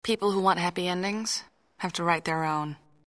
Voice 1 - Voice 2 -
Sex: Female